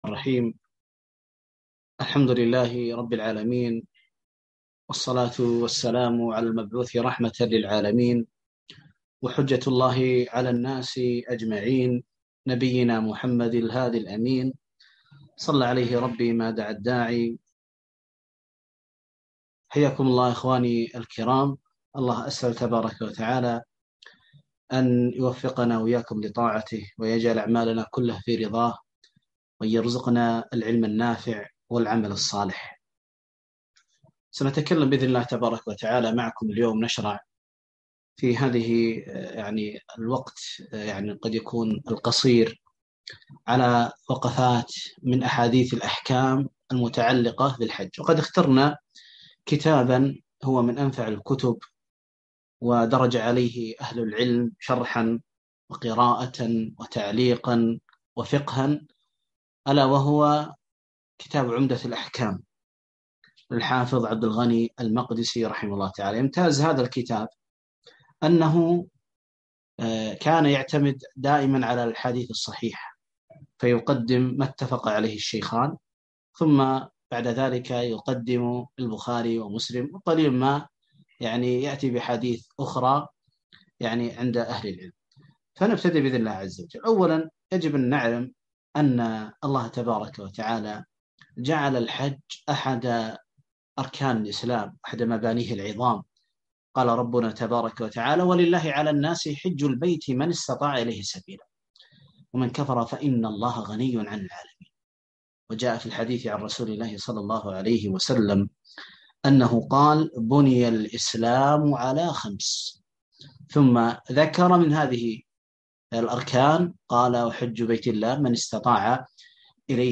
محاضرة - وقفات مع بعض أحاديث الاحكام في الحج